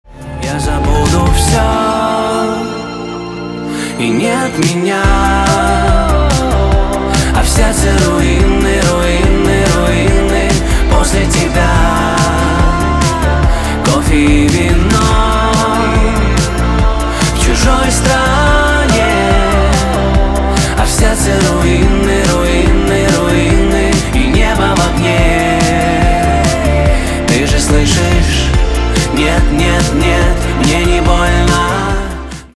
поп
чувственные
битовые